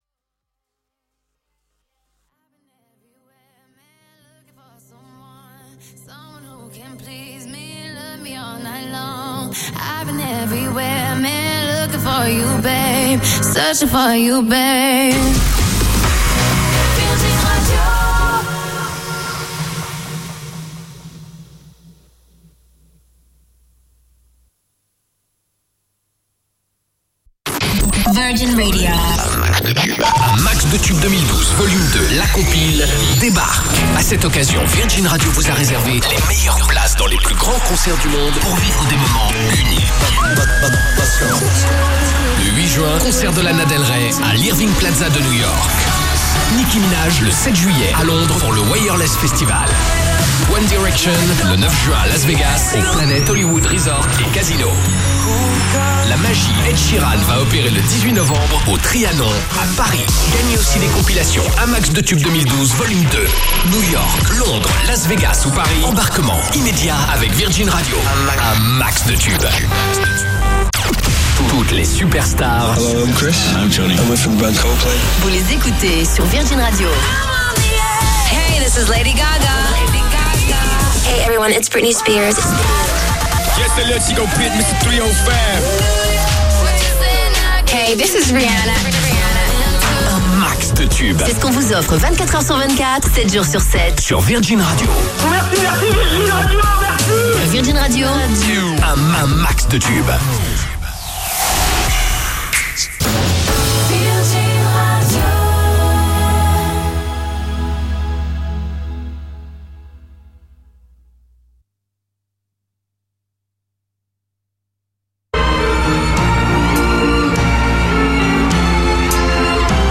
BugVirgin.mp3